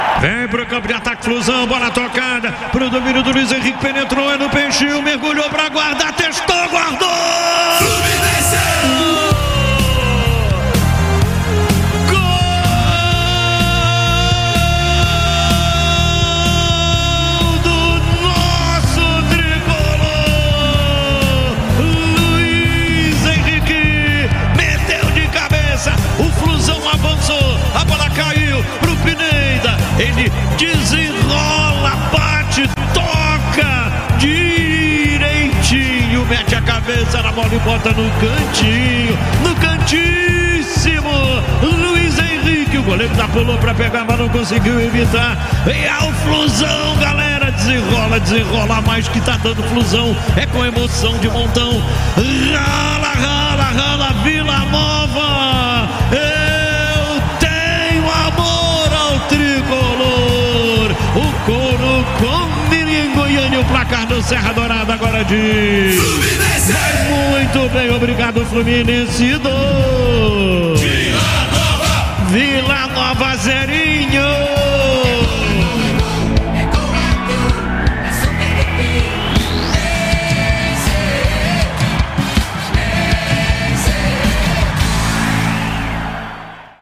Ouça os gols da vitória do Fluminense sobre o Vila Nova com a narração de Luiz Penido